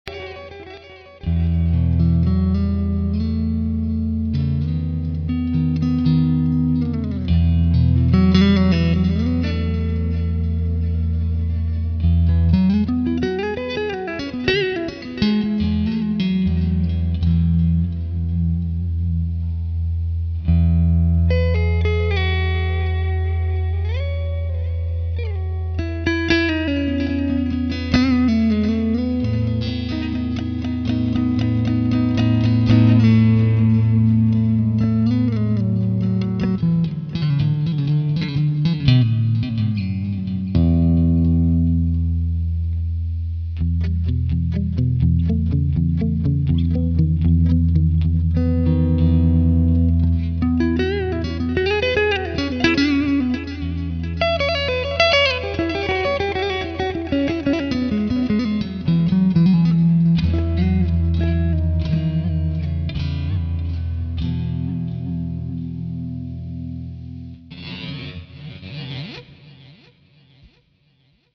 Sweet Clean Space
AMPSweetCleanSpace.mp3